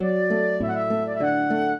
flute-harp
minuet2-6.wav